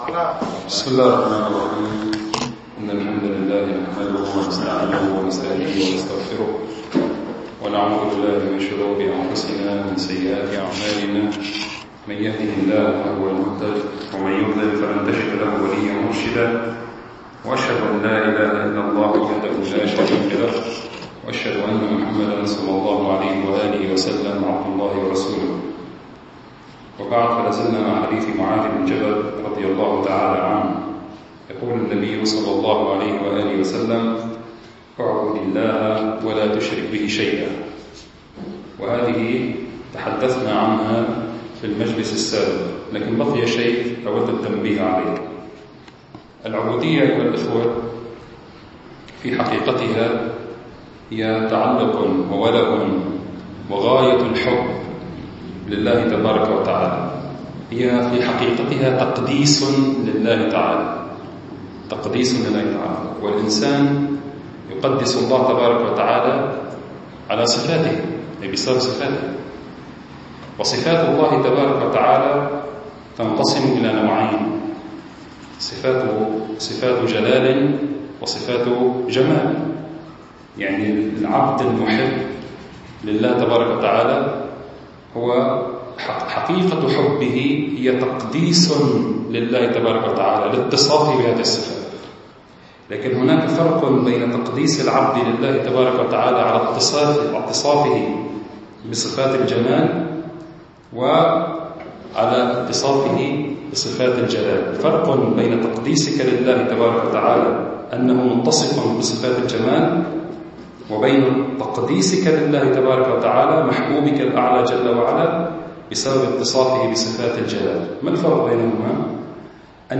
المكان : مركز جماعة عباد الرحمن